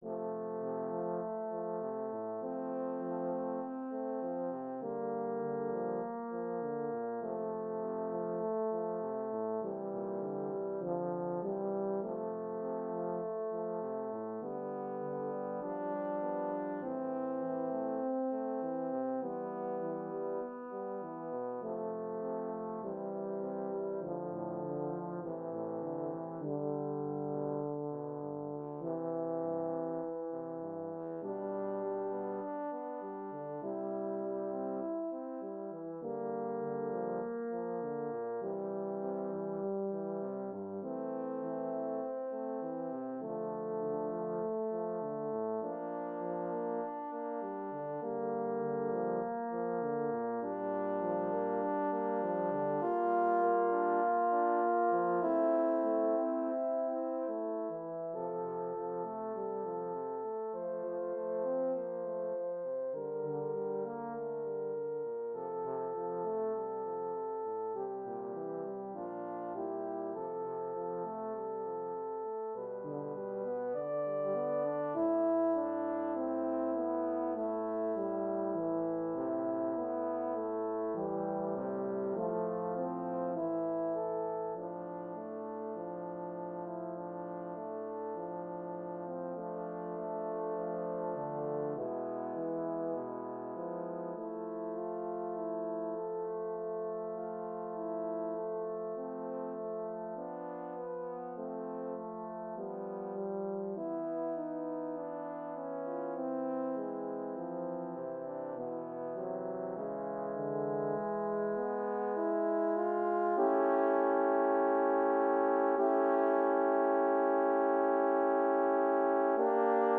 Intermezzo
Per quartetto di Corni